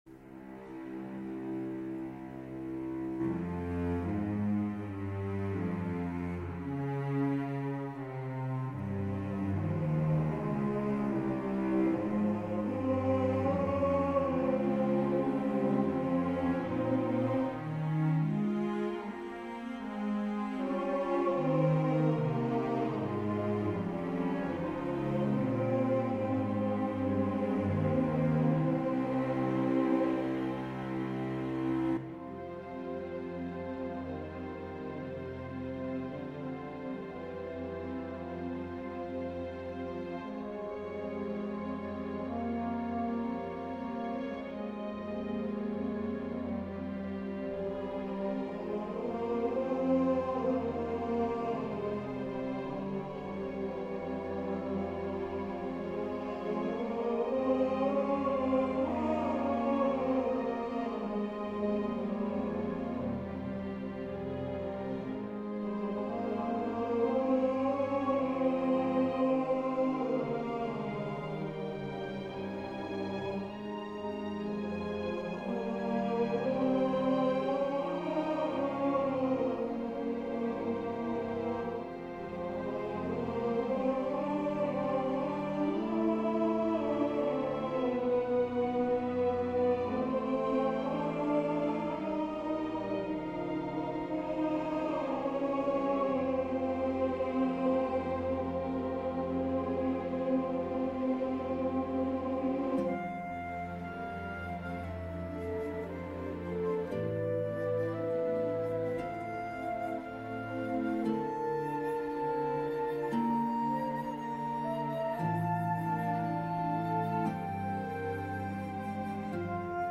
Somber words from Isaiah 40:6-8
Aria for baritone, piano